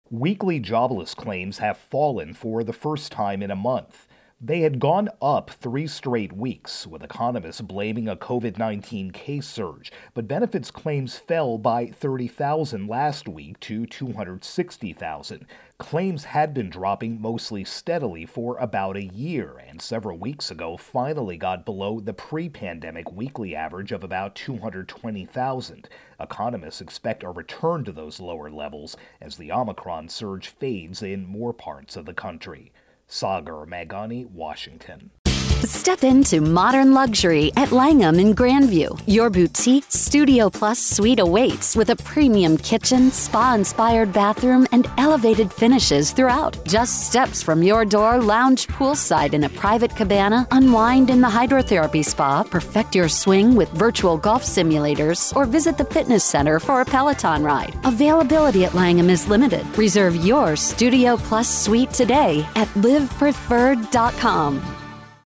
Unemployment Benefits intro and voicer.